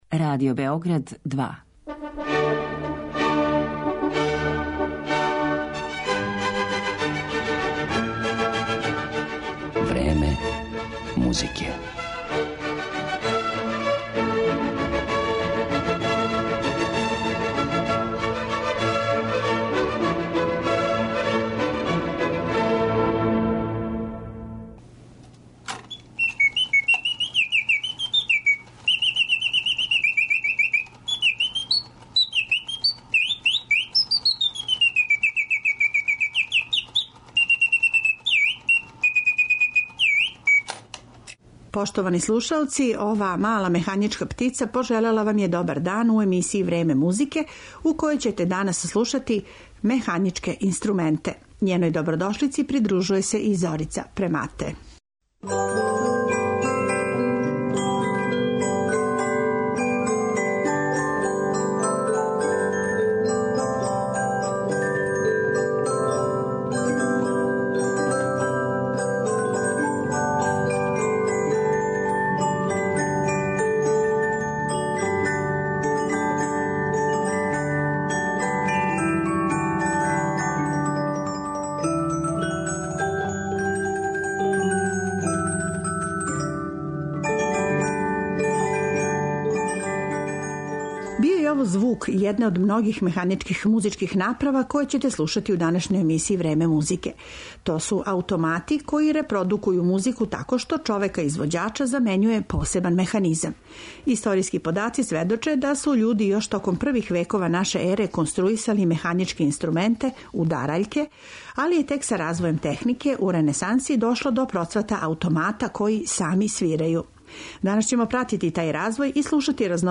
Данас ћете чути како звуче многе необичне и ретке музичке справе, аутомати и машине, а уз њих и причу о настанку, развоју и разноврсној употреби најразличитијих музичких механизама. Емисију ћемо украсити и делима која су за такве механичке инструменте компоновали, током историје музике све до данашњих дана: Хендл, Хајдн, Моцарт, Бетовен, Стравински, Ненкероу и Антеј.